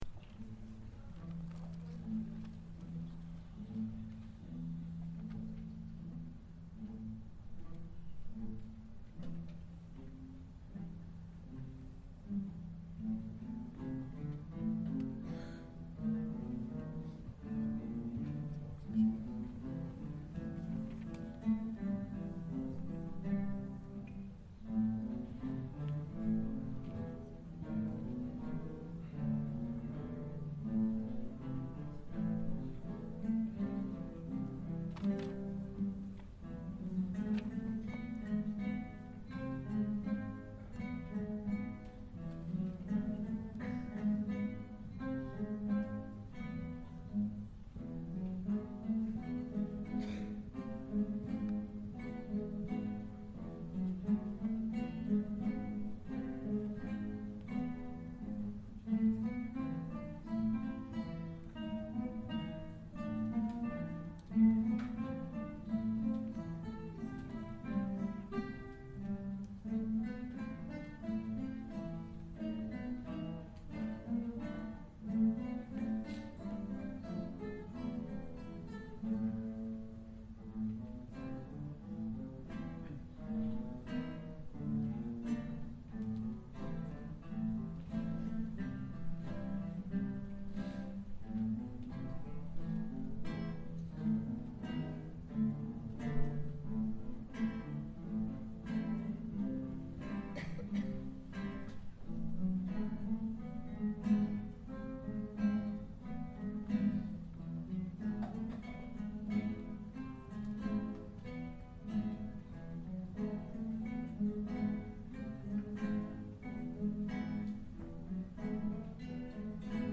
In the Hall of the Mountain King - Senior Guitar Ensemble